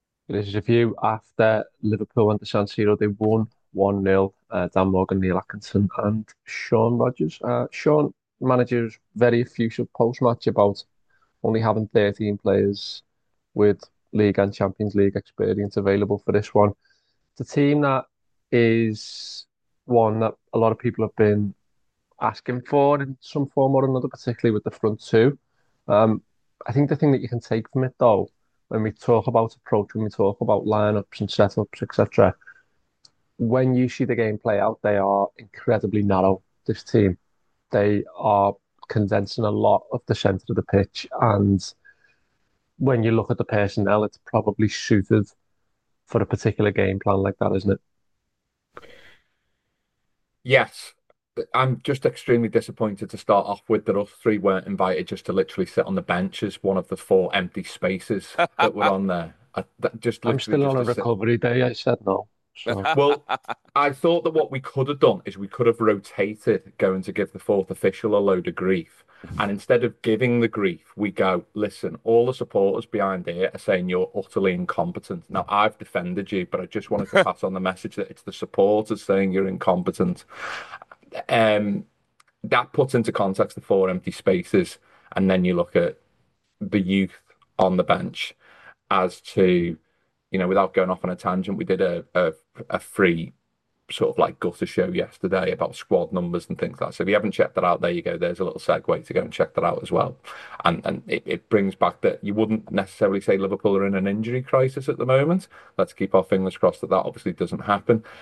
Below is a clip from the show – subscribe for more review chat around Inter Milan 0 Liverpool 1…